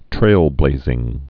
(trālblāzĭng)